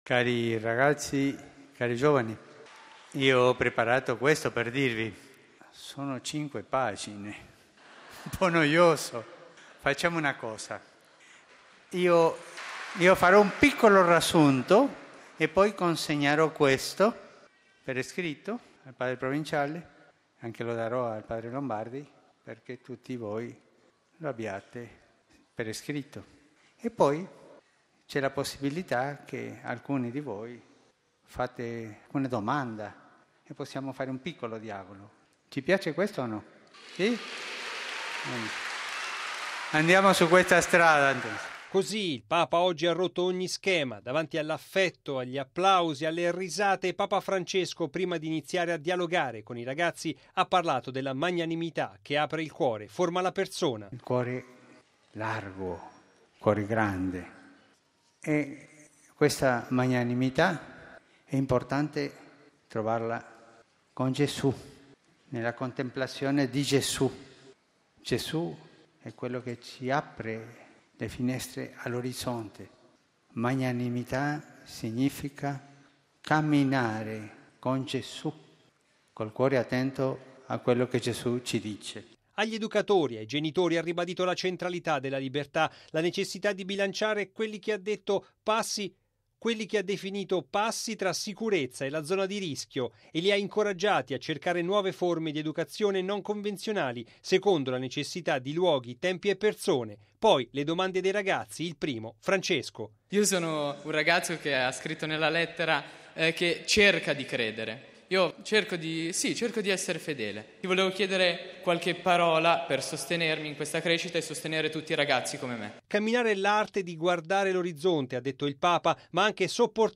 Tanto l’affetto e la gioia dei ragazzi che hanno sollecitato il Papa con le loro domande e Francesco, rompendo ogni schema, ha avviato un dialogo diretto con i giovani.
Così il Papa oggi ha rotto ogni schema davanti all’affetto, gli applausi, le risate.